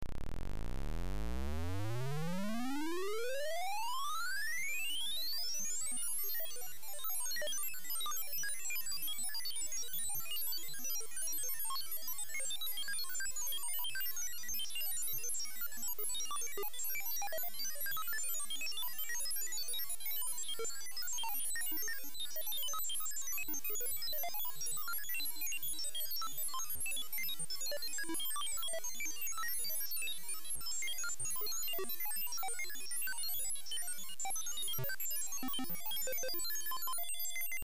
glitch.mp3